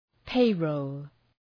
{‘peırəʋl}